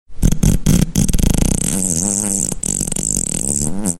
Sound Effects
Fart Poop